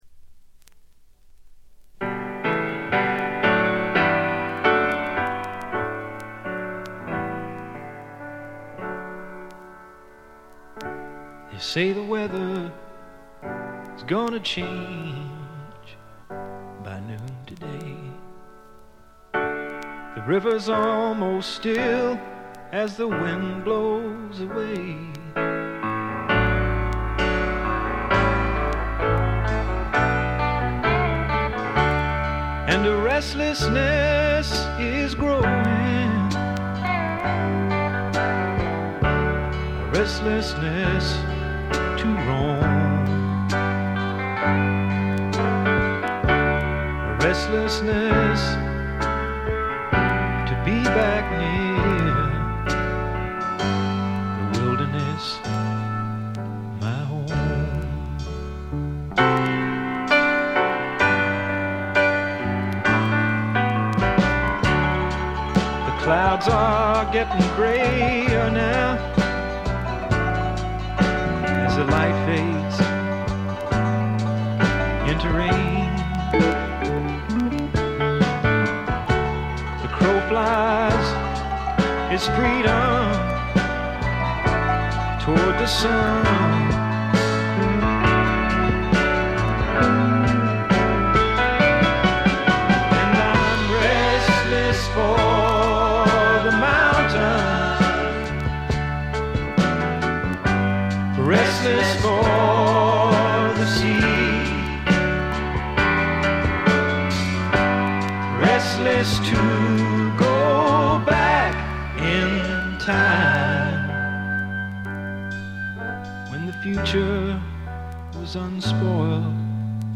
これ以外は軽微なチリプチ少々。
アーシーなシンガー・ソングライターがお好きな方ならば文句なしでしょう。
試聴曲は現品からの取り込み音源です。